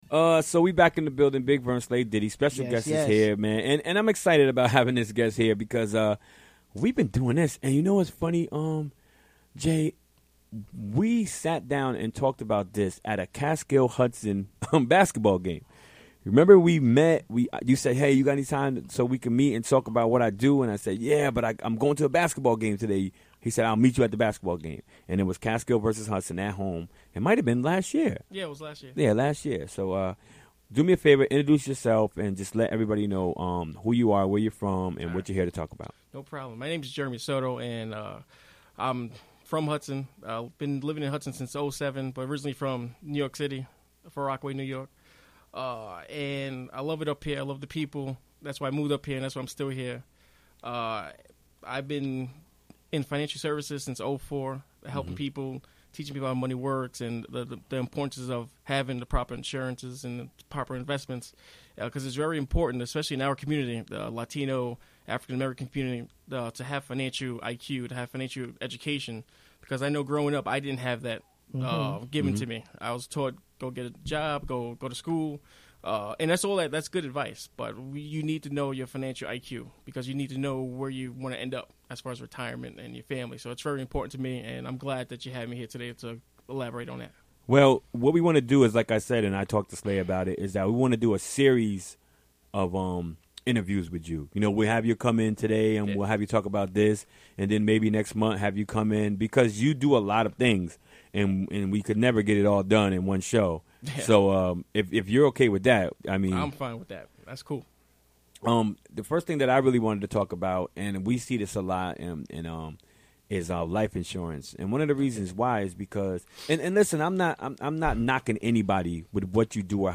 Recorded during the WGXC Afternoon Show Wednesday, December 14, 2016.